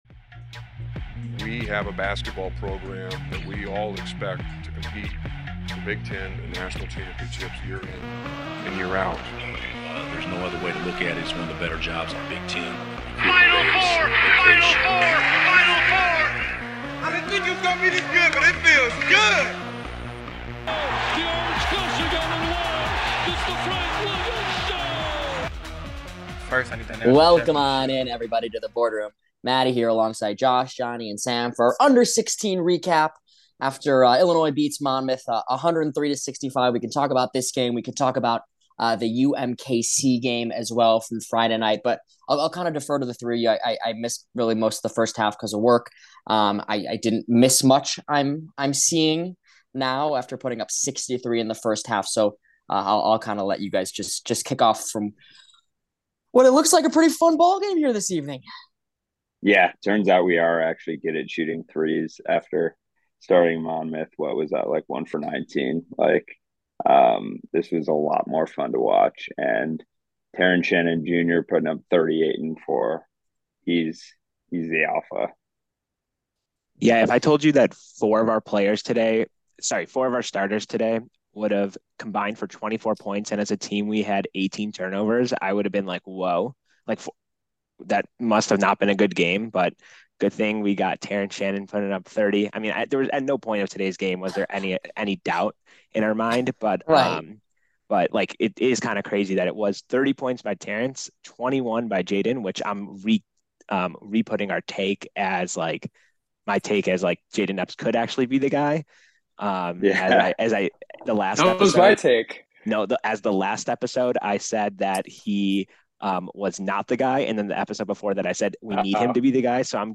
All four of us are on to recap the last two Illini tune-up games before Vegas.